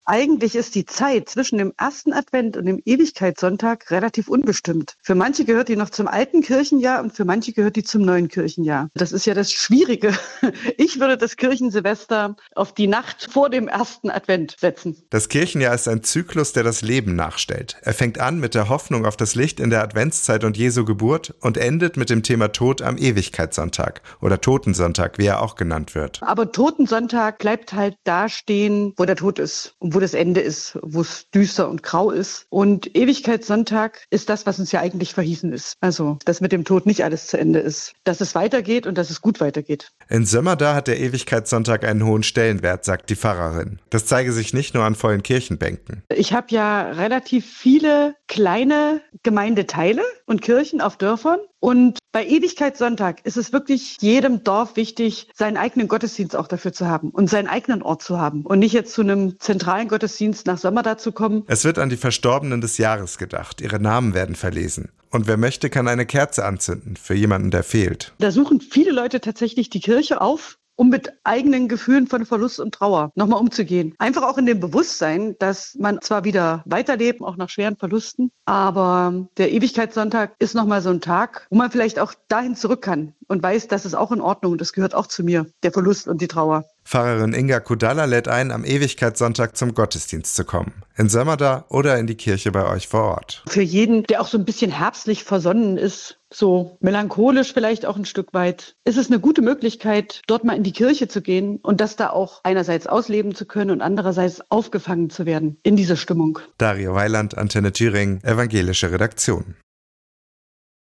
iad-antenne-thueringen-die-woche-zwischen-den-kirchenjahren-43805.mp3